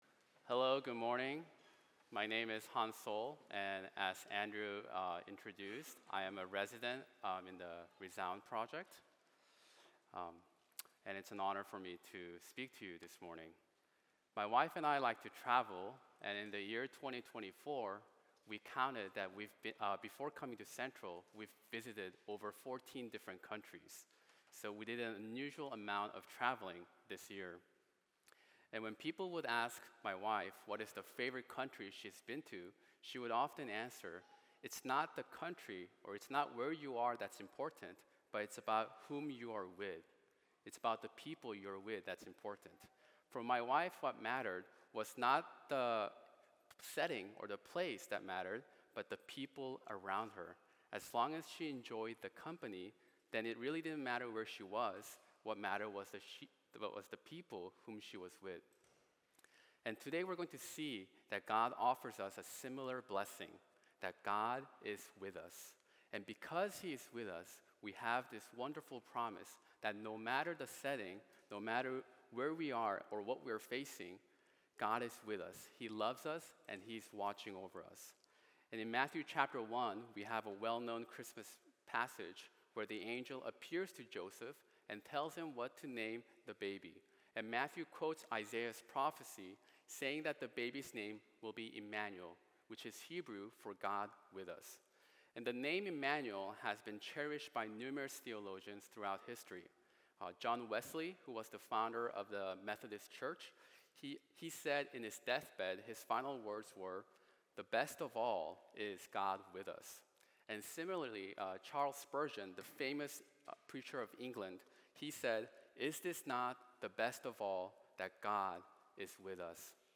Standalone Sermon: God With Us